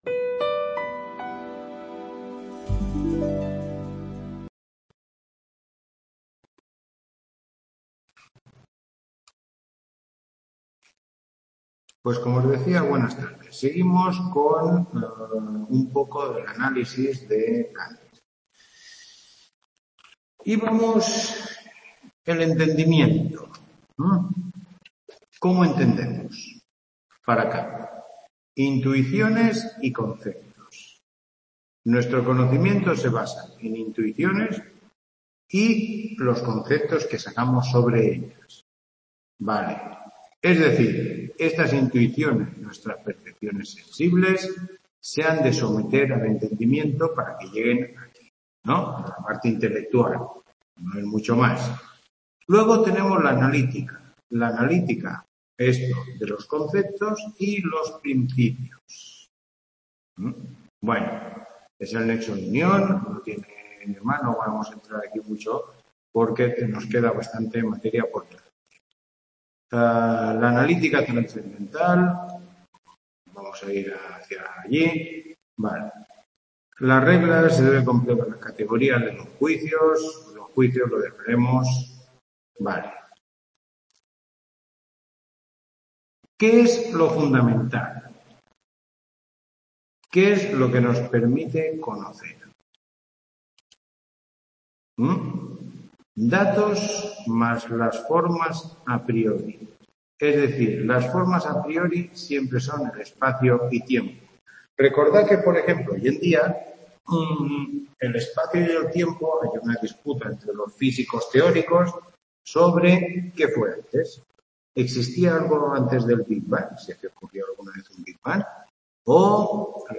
Tutoría 4